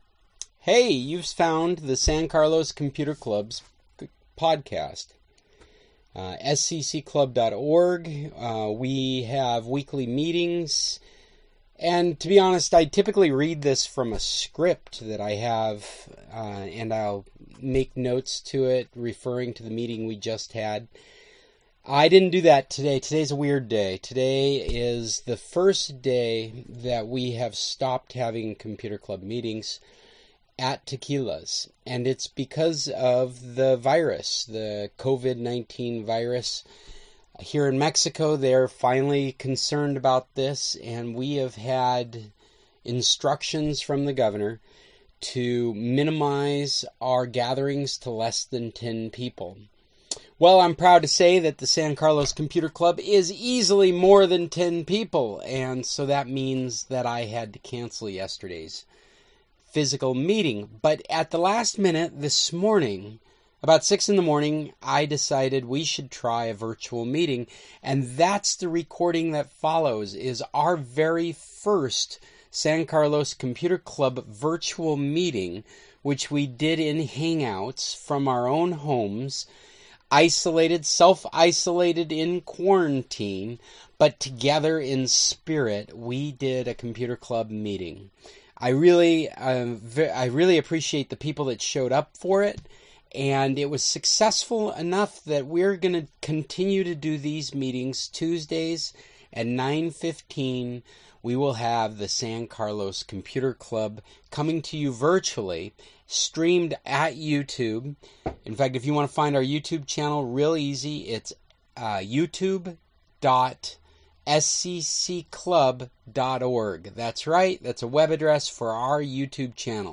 March 17, 2020 :24: Our Self-Isolated Quarantined Virtual Meeting #1